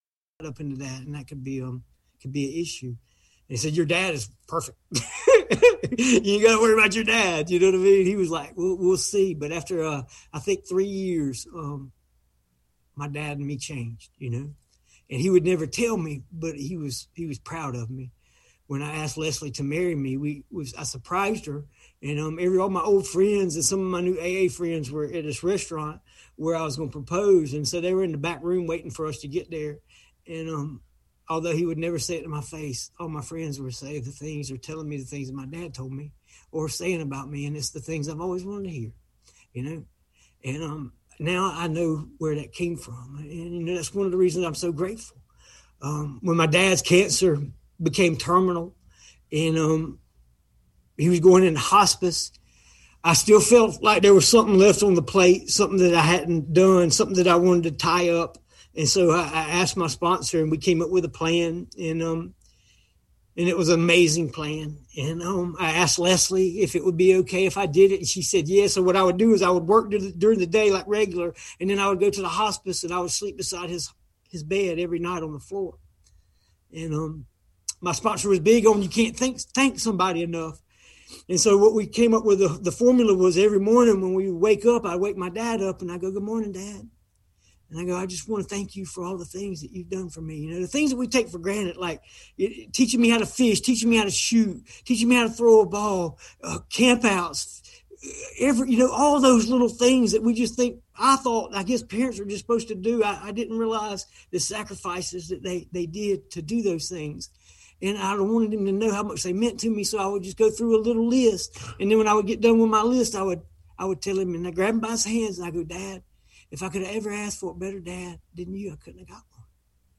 Georgia State Al-Anon/Alateen Convention - 2021 - Virtual